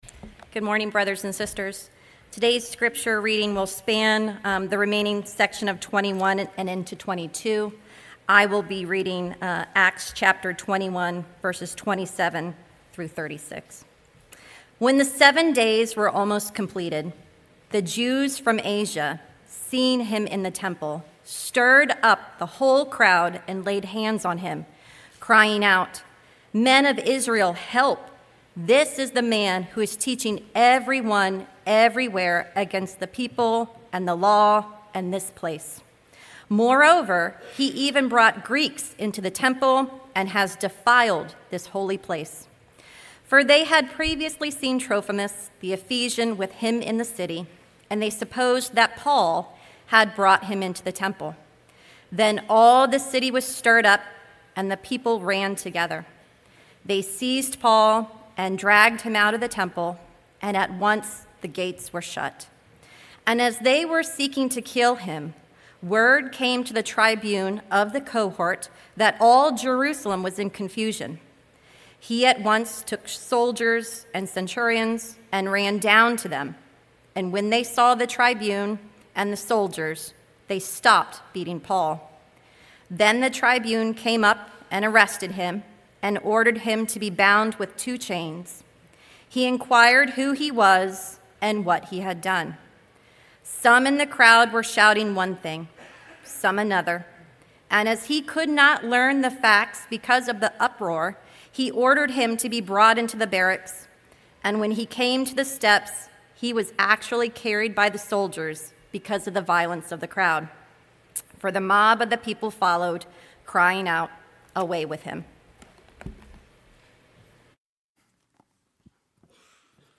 sermon9.14.25.mp3